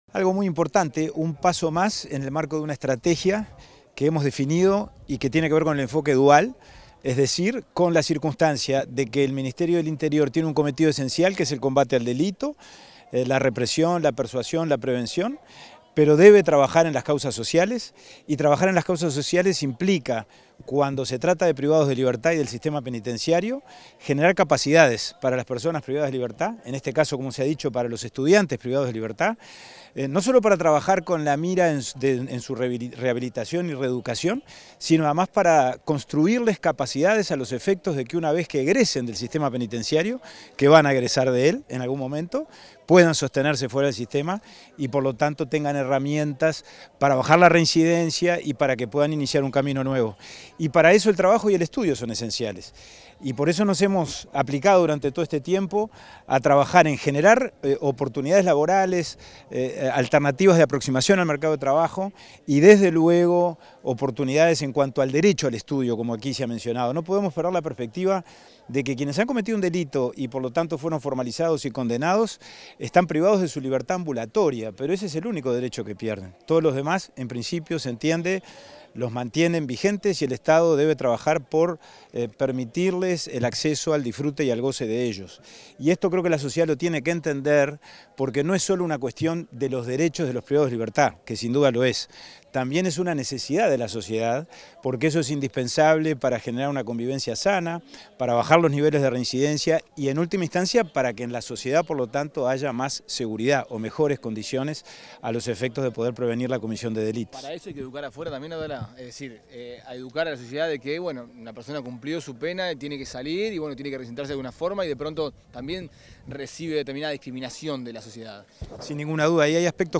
Declaraciones del subsecretario del Interior, Pablo Abdala
Declaraciones del subsecretario del Interior, Pablo Abdala 11/04/2024 Compartir Facebook Twitter Copiar enlace WhatsApp LinkedIn Tras la inauguración del Centro Universitario en la Unidad 3 del Instituto Nacional de Rehabilitación (INR), este 11 de abril, el subsecretario del Ministerio del Interior, Pablo Abdala, realizó declaraciones a la prensa.